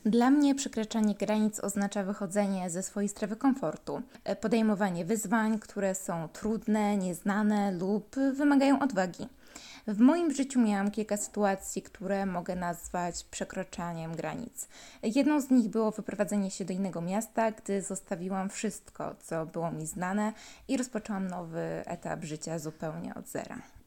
Zapytaliśmy studentów, co według nich oznacza “przekraczanie granic” i czy mieli okazję podjąć się tego w życiu: